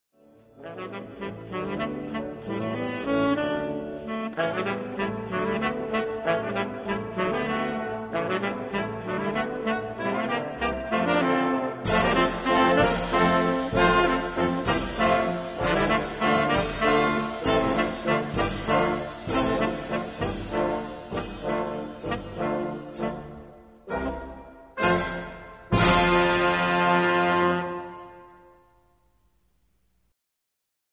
（テナーサックス+ピアノ）